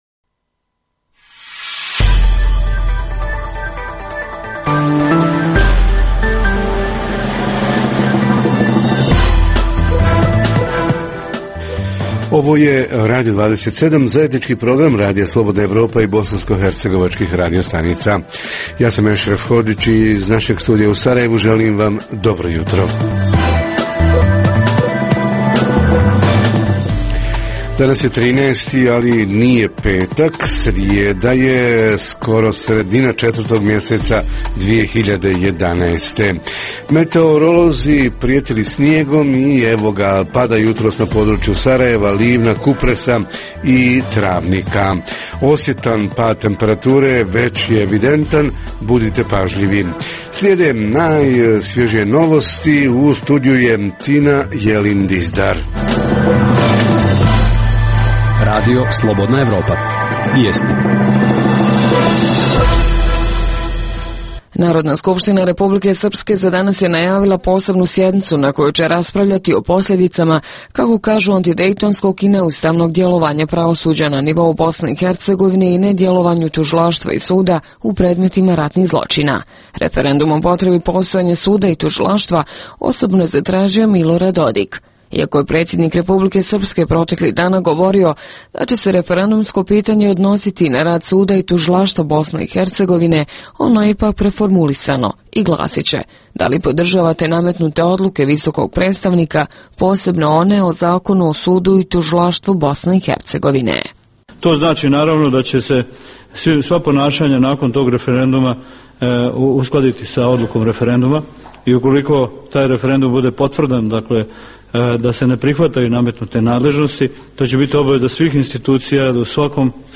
Reporteri iz cijele BiH javljaju o najaktuelnijim događajima u njihovim sredinama. Redovna rubrika Radija 27 srijedom je “Vaša prava".